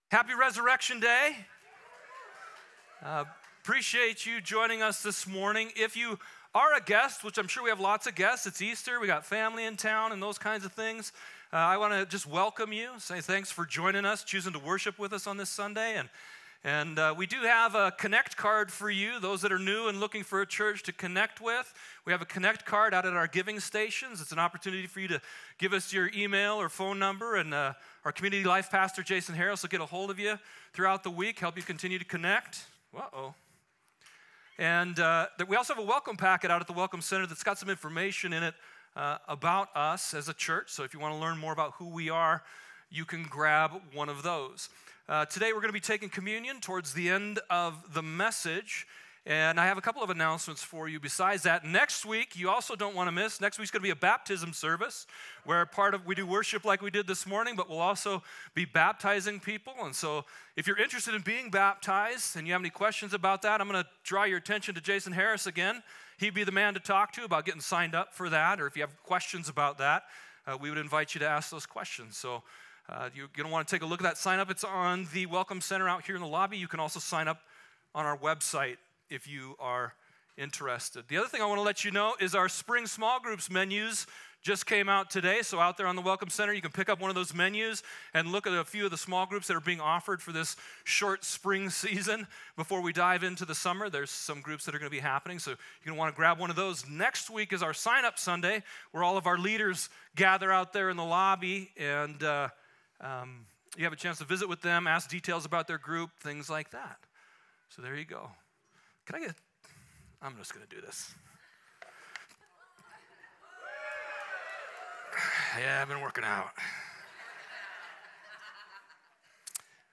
Easter Sunday Service 2025 - Mount Helena Community Church
easter-sunday-service-2025.mp3